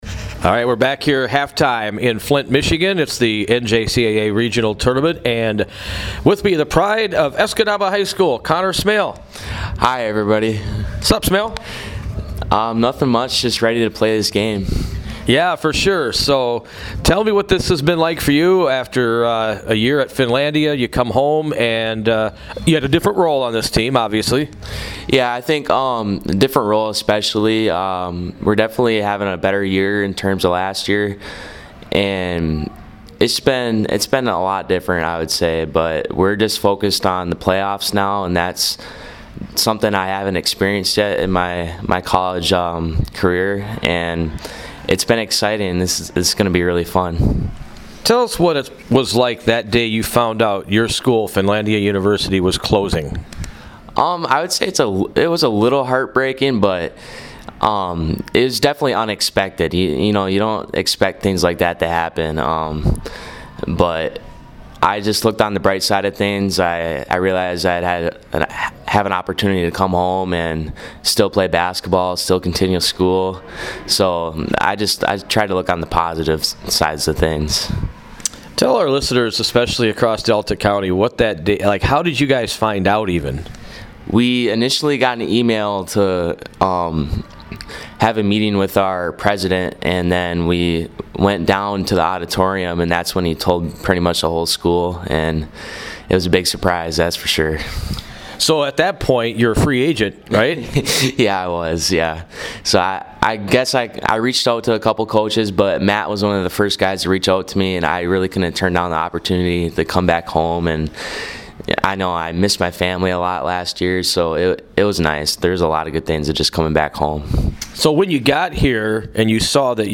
player spotlight interview